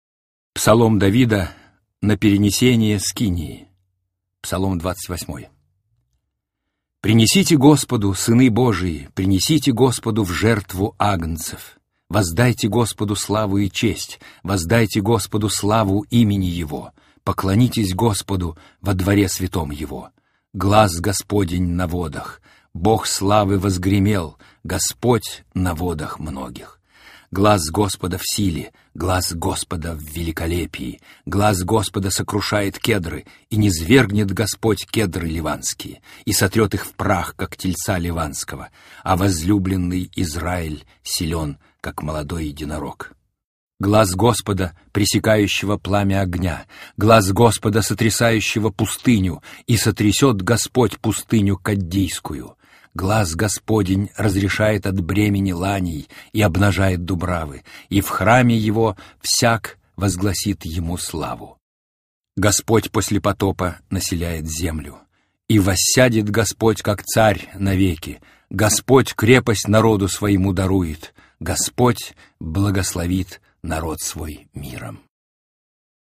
• Qualidade: Alto